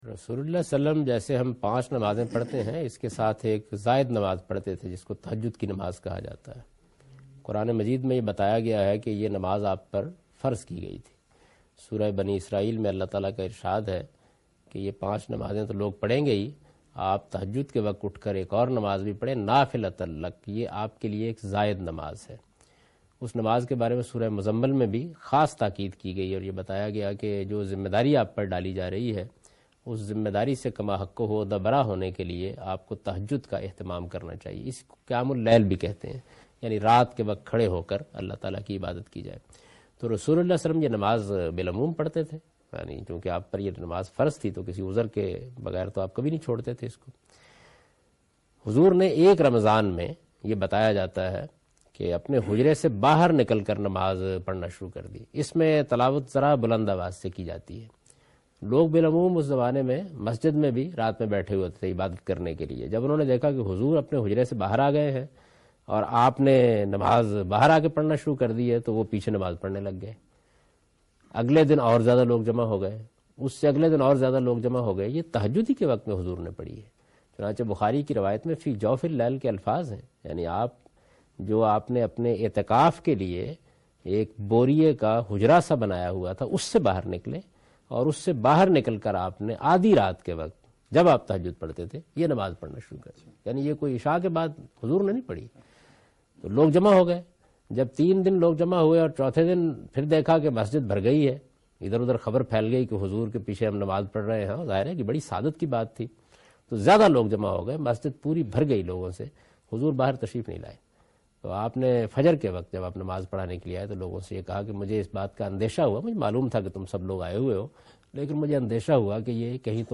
Javed Ahmad Ghamidi is explaining Traveeh, Tahajud and Witar during Q&A session at Al-Mawrid Lahore